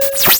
贡献 ） 分类:游戏音效 您不可以覆盖此文件。